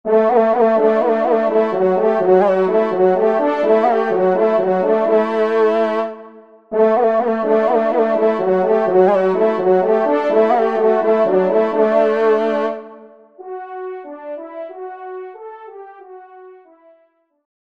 Genre : Fantaisie Liturgique pour quatre trompes
Pupitre 3° Trompe